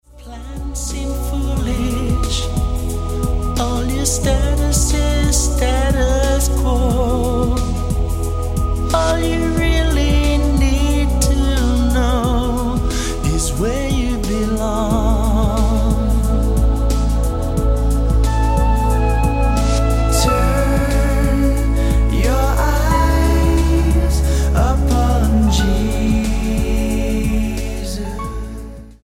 STYLE: Rock
A fine rock set.